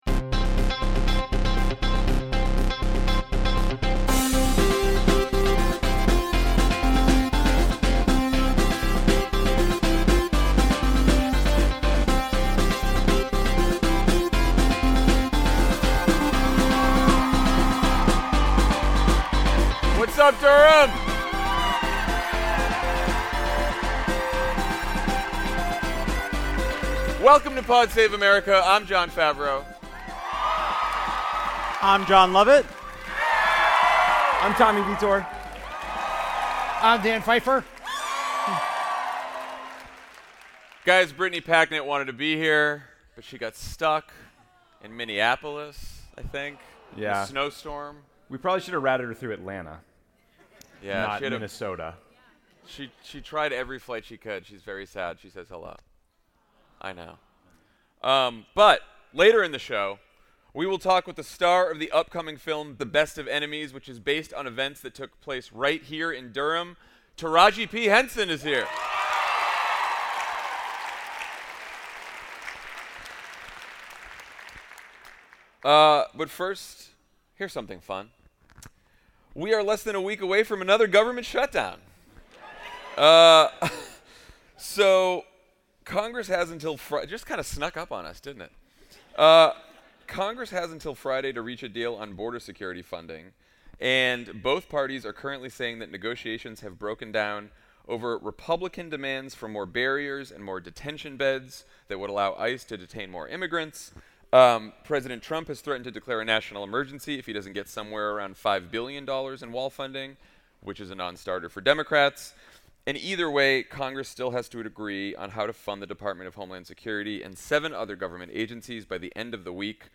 (LIVE in Durham)
Shutdown negotiations falter as Trump takes his demagoguery to El Paso, Elizabeth Warren and Amy Klobuchar formally launch their presidential campaigns, and “The Best of Enemies” star Taraji P. Henson joins Jon, Jon, Tommy, and Dan live on stage in Durham, North Carolina.